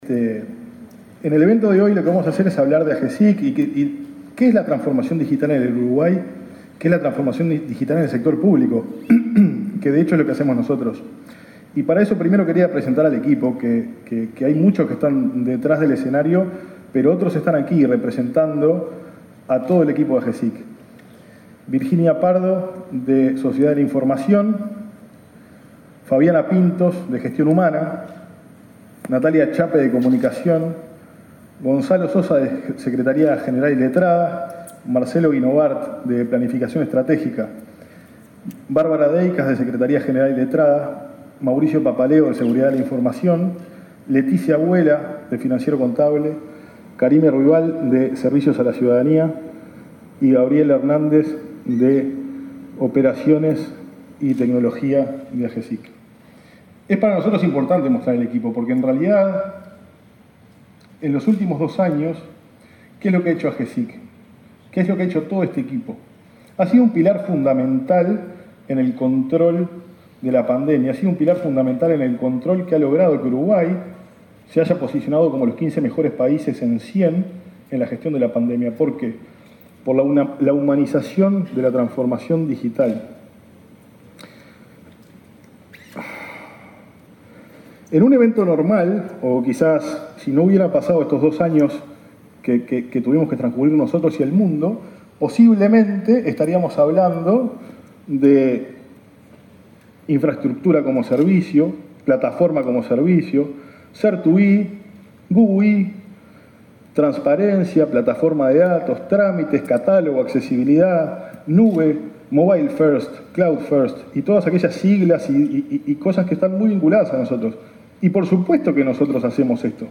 Palabras del director ejecutivo de Agesic, Hebert Paguas
Palabras del director ejecutivo de Agesic, Hebert Paguas 12/10/2022 Compartir Facebook X Copiar enlace WhatsApp LinkedIn El director ejecutivo de la Agencia de Gobierno Electrónico y Sociedad de la Información y del Conocimiento (Agesic), Hebert Paguas, expuso este miércoles 12 en la Torre Ejecutiva sobre el rol del Gobierno en la transformación digital.